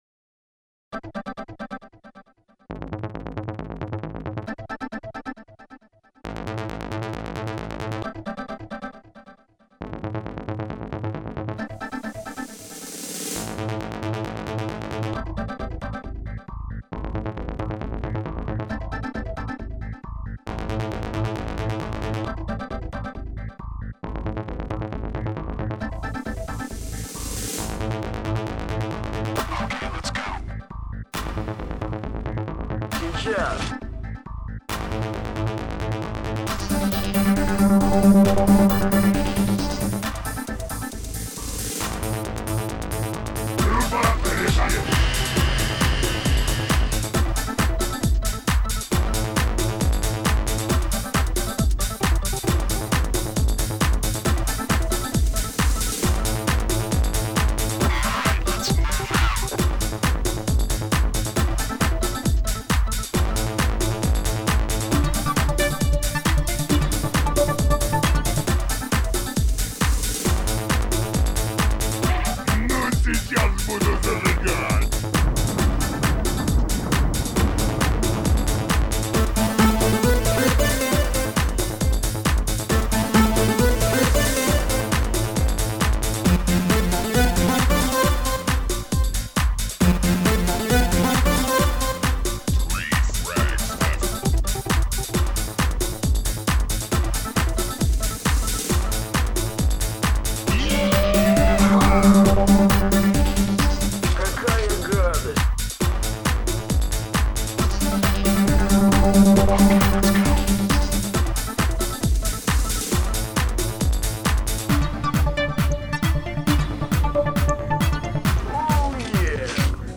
Музыкальные файлы записаны с помощью софта под общим названием ACID, речевые с помощью говорилки и голосового движка Алены.